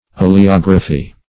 Search Result for " oleography" : The Collaborative International Dictionary of English v.0.48: Oleography \O`le*og"ra*phy\, n. 1.